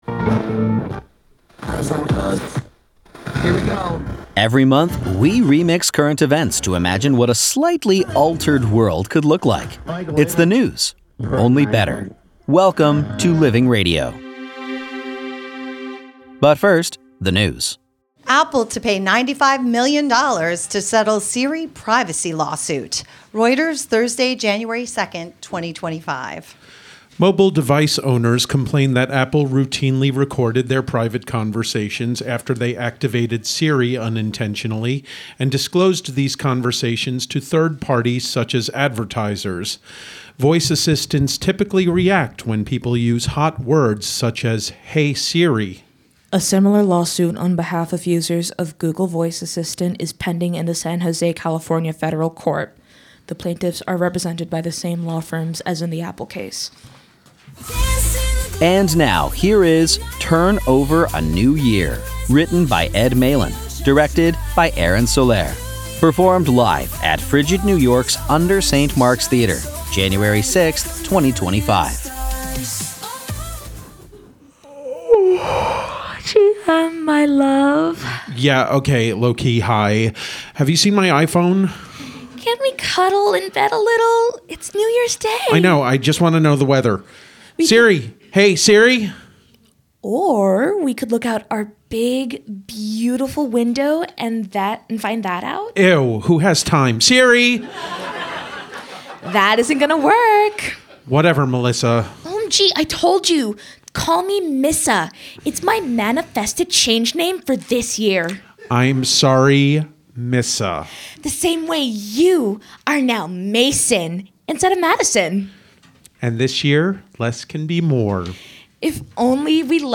performed live at UNDER St. Mark’s Theater, January 6, 2025